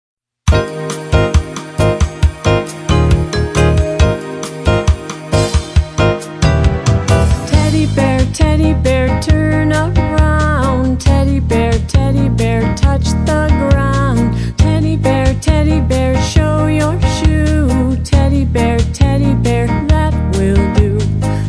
Nursery Rhyme
Action & Exercise